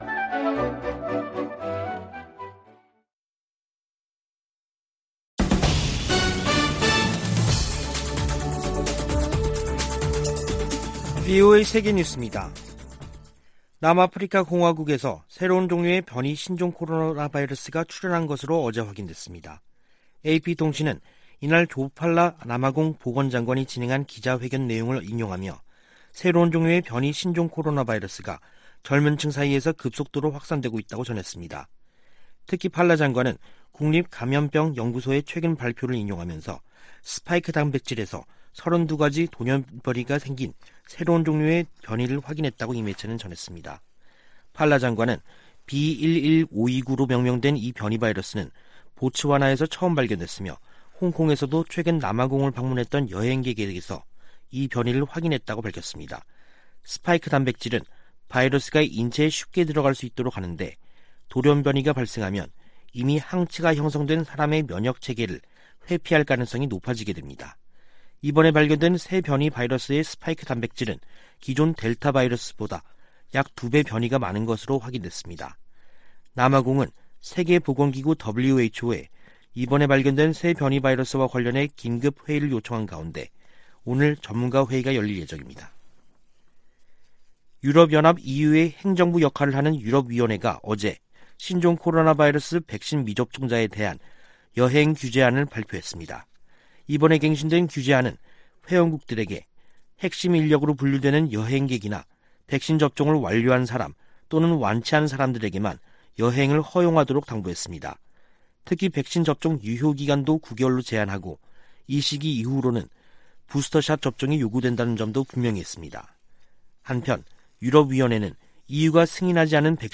VOA 한국어 간판 뉴스 프로그램 '뉴스 투데이', 2021년 11월 26일 2부 방송입니다. 미 국무부는 북한의 계속된 핵 활동을 규탄하면서, 북한과의 비핵화 대화를 추구하고 있다고 밝혔습니다. 미국 상무부가 북한 유령회사에 미국과 다른 국가의 기술을 판매한 중국 기업 등, 국가안보에 위협이 되는 해외 기업들을 수출 규제 대상으로 지정했습니다. 북한 국영 고려항공이 또다시 유럽연합 회원국 내 운항이 엄격히 제한되는 항공사로 지정됐습니다.